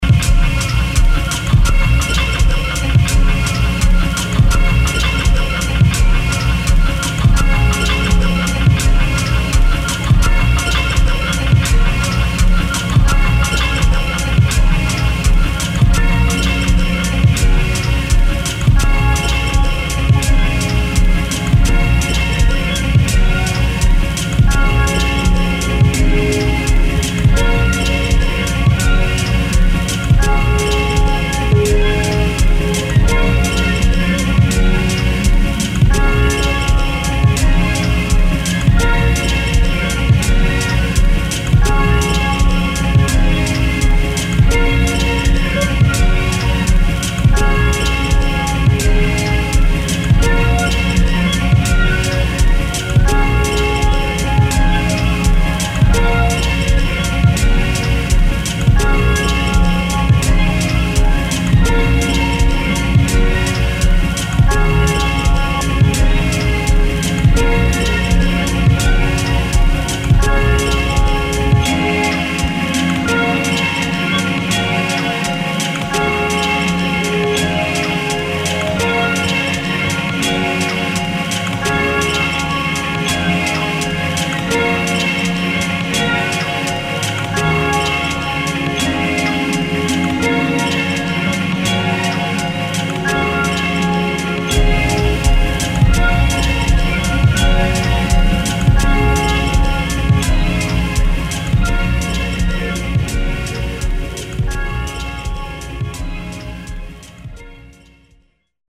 [ TECHNO | AMBIENT ]